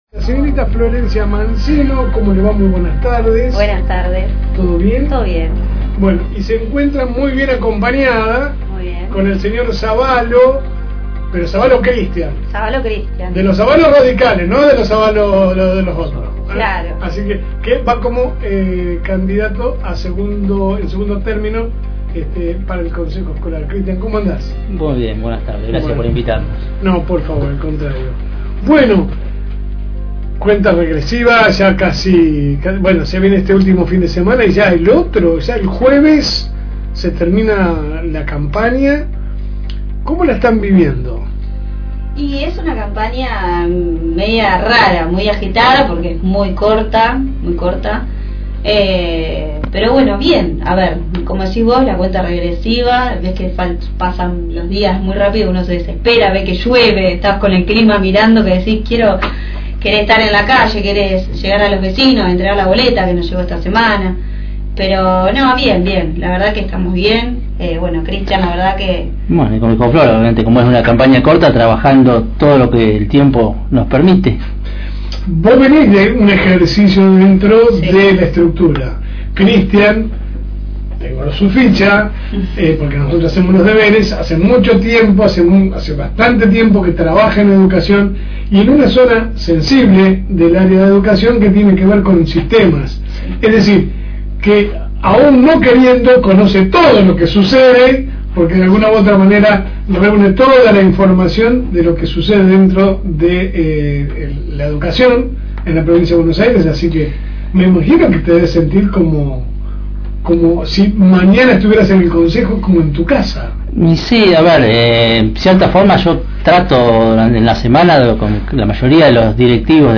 Espacio Electoral.